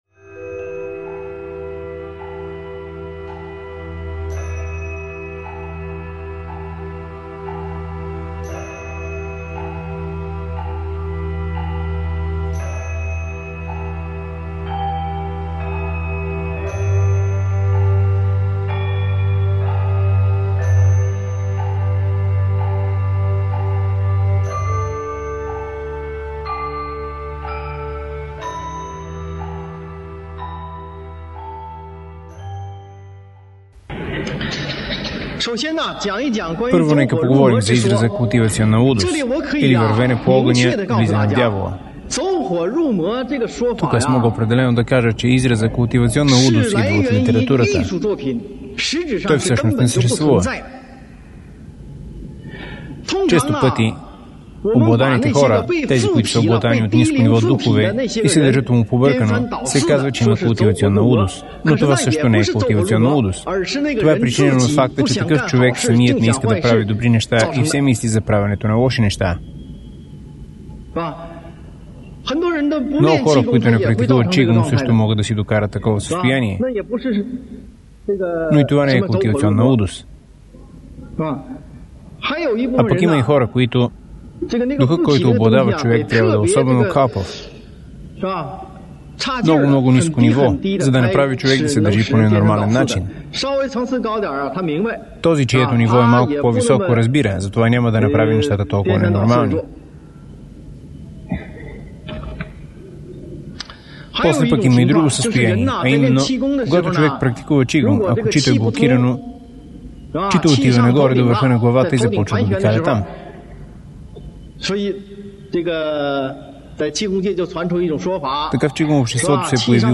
Лекция 1